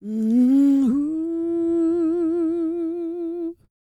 E-CROON P318.wav